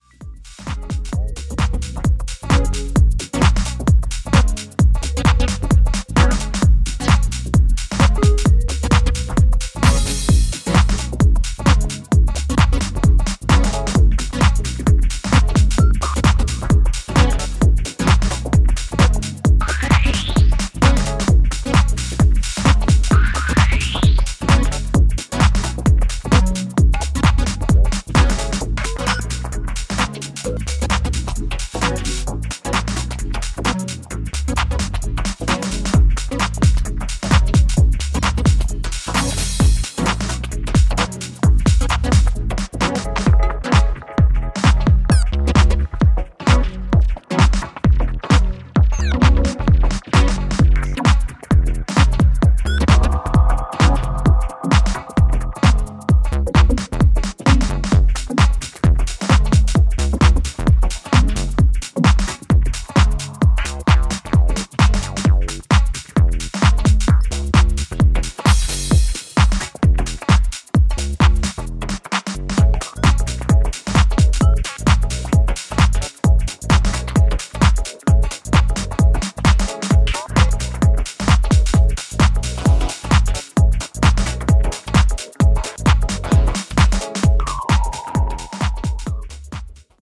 deep funk